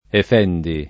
efendi [ ef $ ndi ] o effendi [ eff $ ndi ]